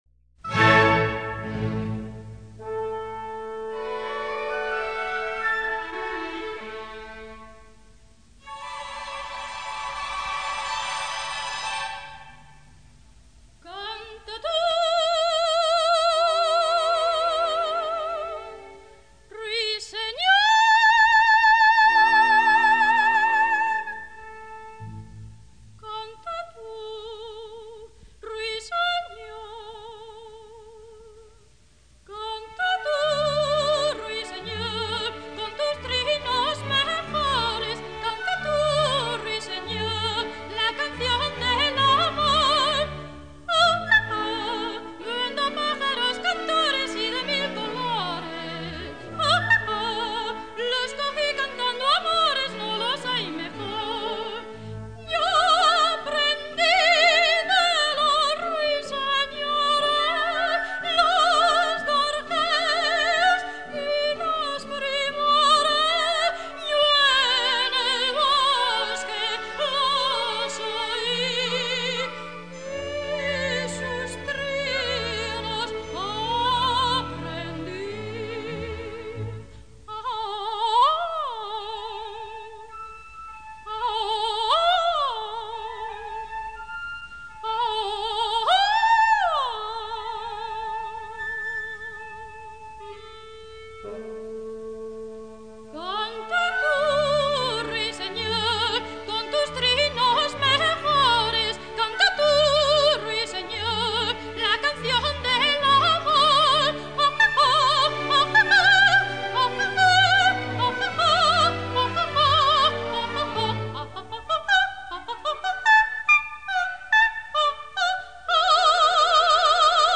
revista en tres cuadros
Coro y Orquesta de Cámara de Madrid